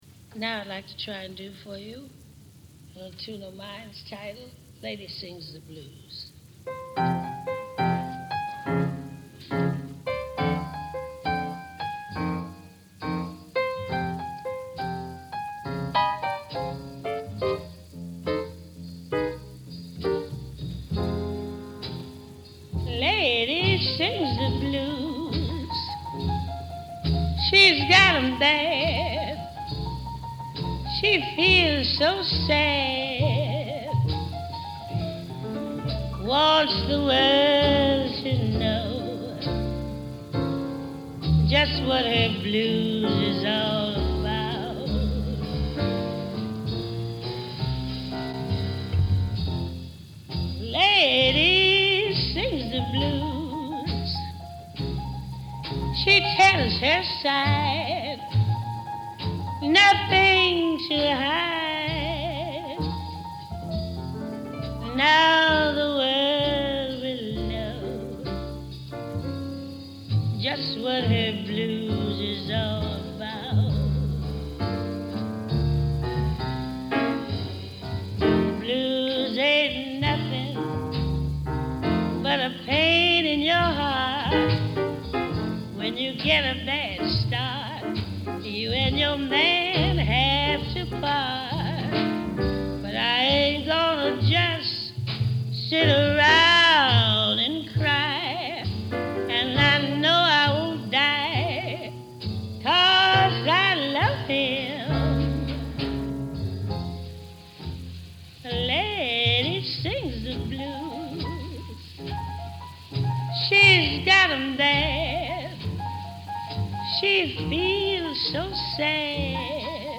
Jazz